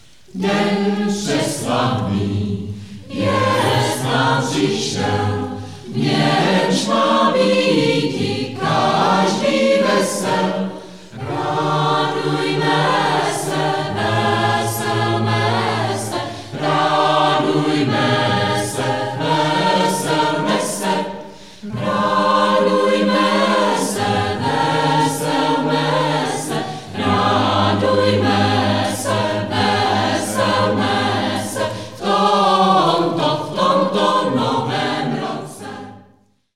Pěvecký sbor
Následující zvukové nahrávky ve formátu mp3 byly pořízeny při adventním koncertu 10. prosince 2006 v prostorách kostela sv. Jiljí v Nebuželích.